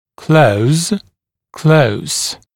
[kləuz] прил. [kləus][клоуз] прил. [клоус]закрывать; близкий, плотный, тщательный